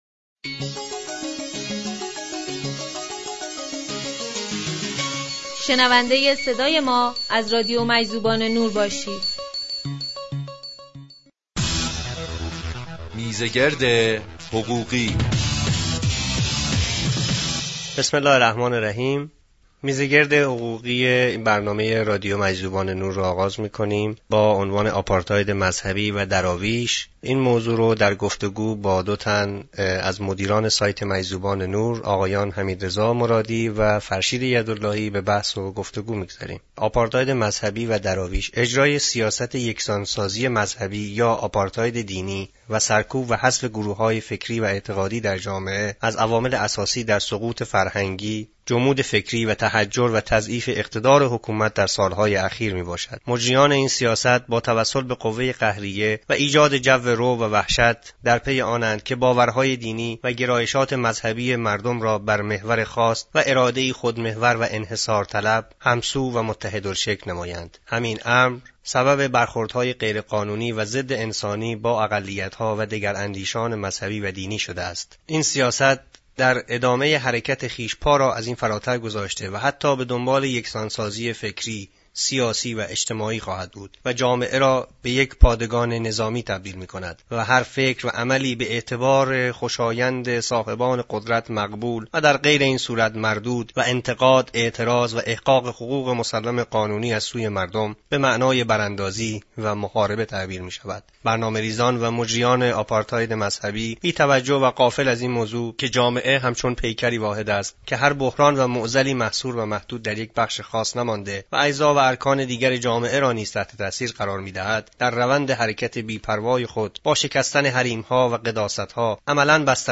برنامه شماره سه: میز گرد حقوقی – آپارتاید مذهبی